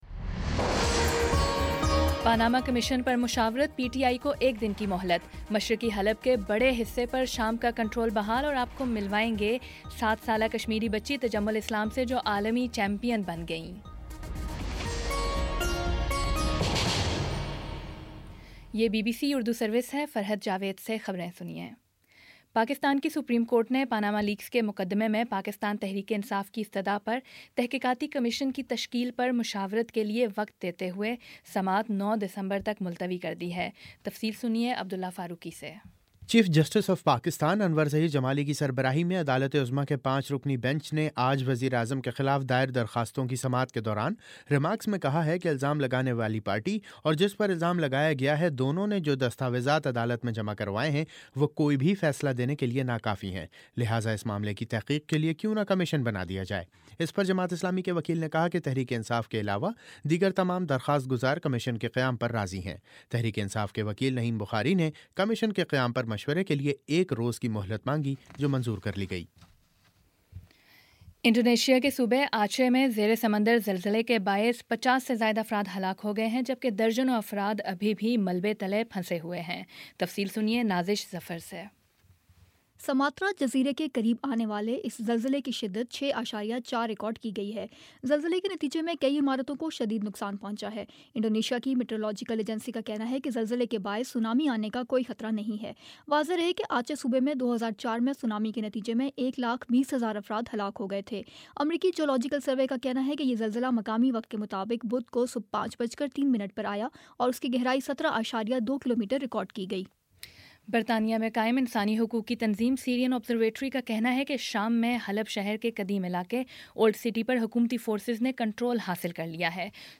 دسمبر 07 : شام پانچ بجے کا نیوز بُلیٹن